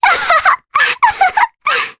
Ophelia-Vox_Happy3_jp.wav